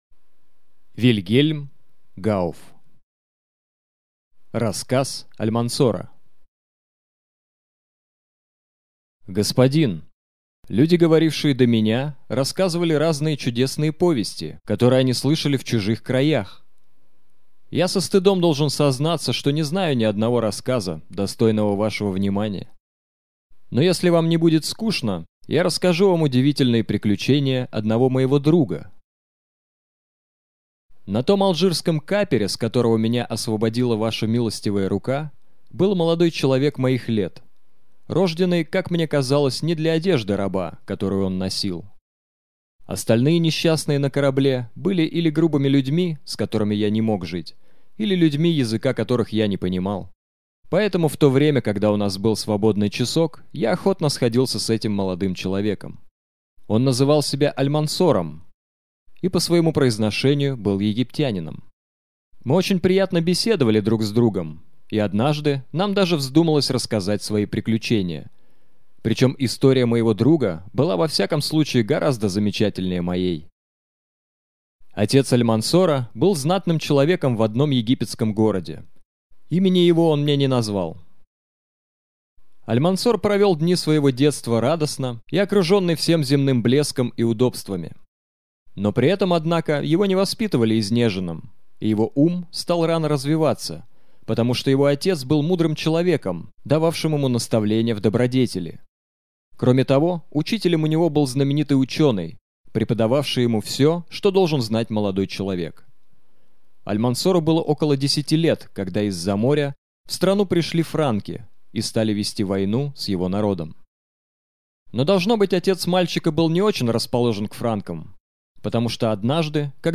Аудиокнига Рассказ Альмансора | Библиотека аудиокниг